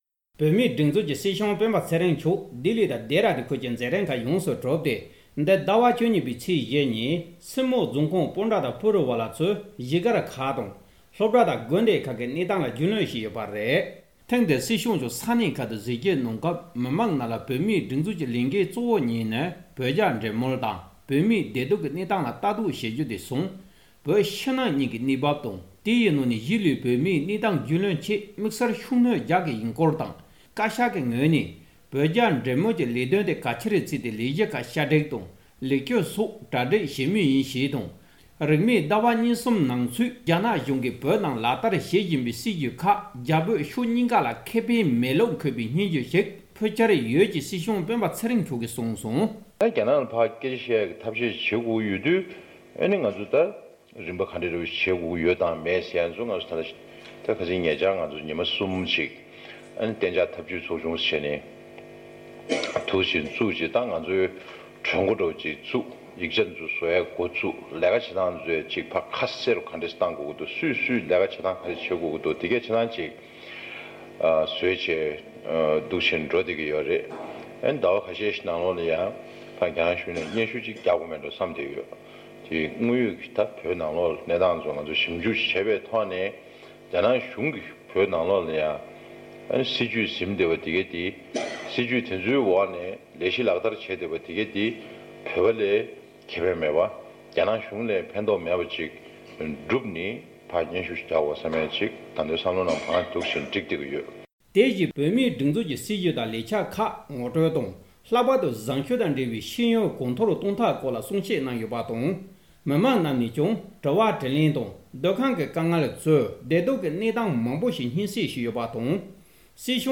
མི་མང་གི་དཀའ་སྡུག་ཁག་ལ་ཞིབ་འཇུག་གནང་སྟེ་དགོས་མཁོ་དང་མཚམས་པའི་ལག་བསྟར་ངེས་པར་དུ་བྱ་རྒྱུ་ཡིན། སྲིད་སྐྱོང་མཆོག་གིས། སྲིད་སྐྱོང་སྤེན་པ་ཚེ་རིང་མཆོག་གིས་སྤུ་རུ་ཝ་ལ་ས་སྐྱ་གཞིས་སྒར་གྱི་བོད་མི་རྣམས་ལ་གསུང་བཤད་གནང་སྐབས།
སྒྲ་ལྡན་གསར་འགྱུར། སྒྲ་ཕབ་ལེན།